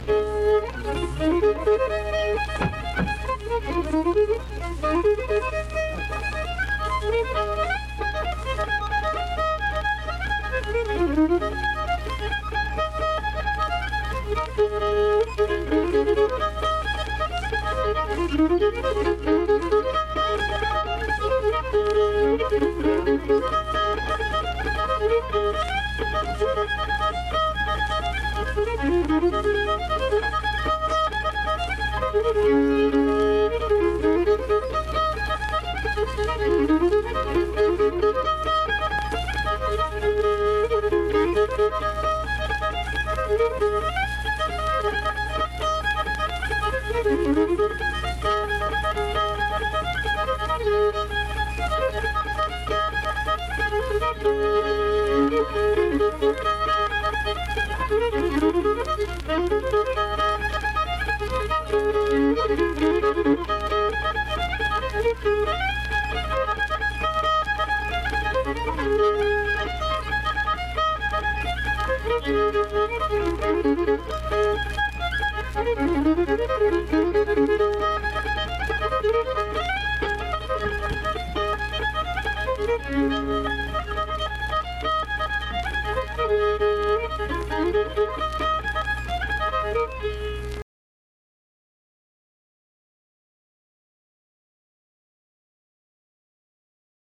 Instrumental fiddle performance.
Instrumental Music
Fiddle
Vienna (W. Va.), Wood County (W. Va.)